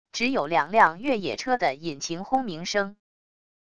只有两辆越野车的引擎轰鸣声wav音频